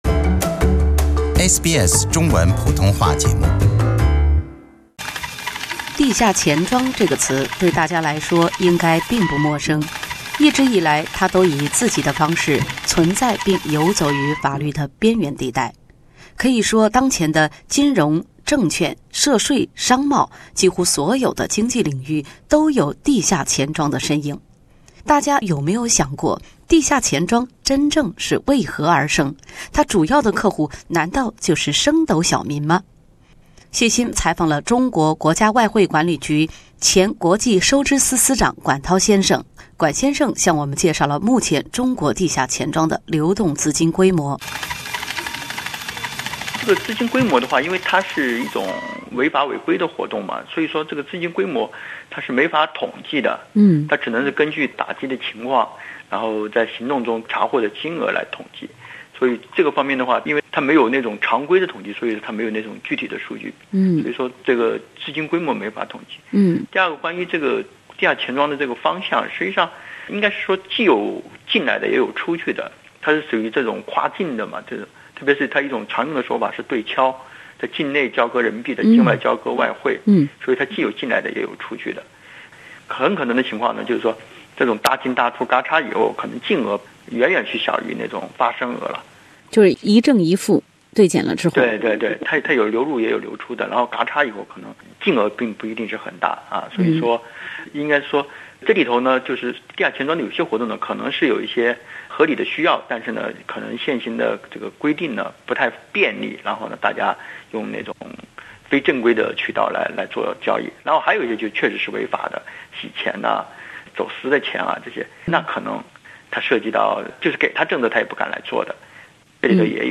中国如何控制地下钱庄？点击收听音频采访。